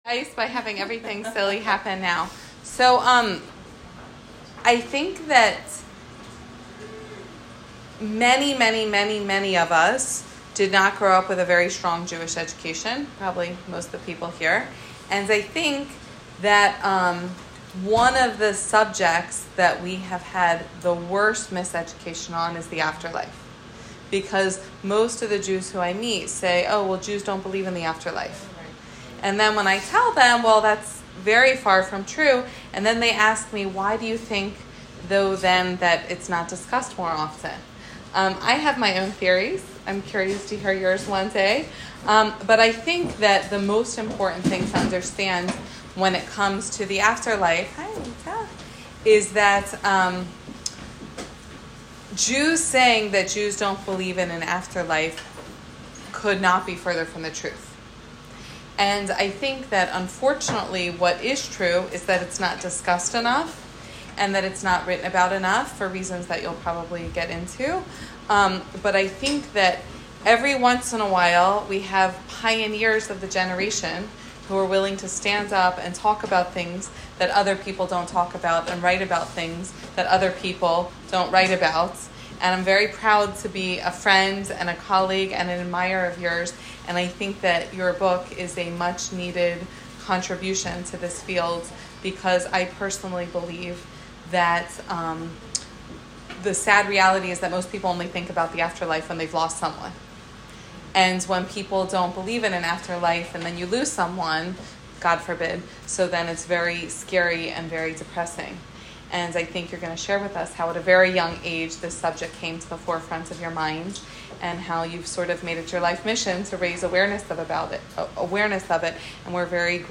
Listen to the talk given to AISH in Philadelphia on